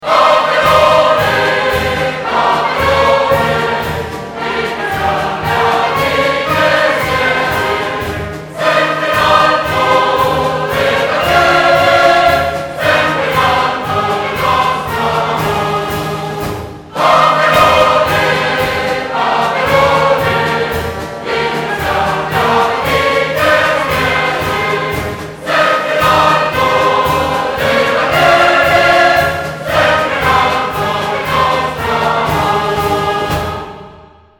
suoneria per smartphone